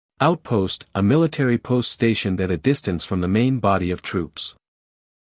英音/ ˈaʊtpəʊst / 美音/ ˈaʊtpoʊst /